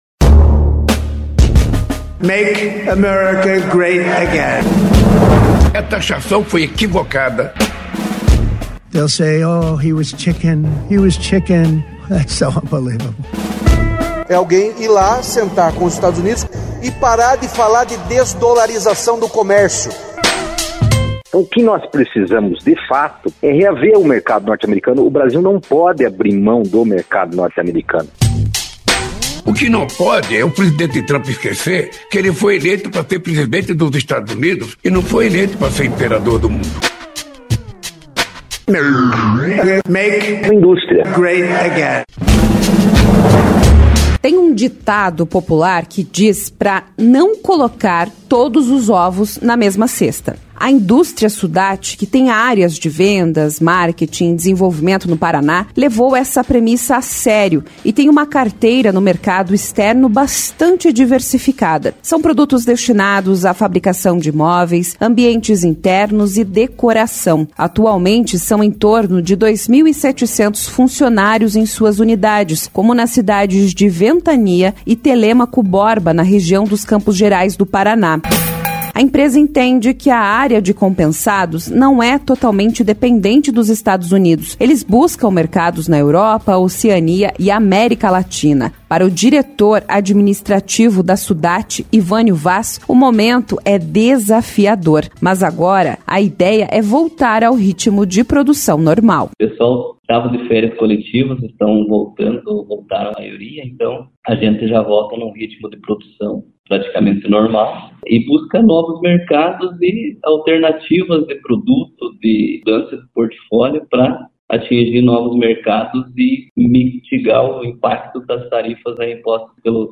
Reportagem 2 – Caixeiro Viajante: saídas, diversificação e novos mercados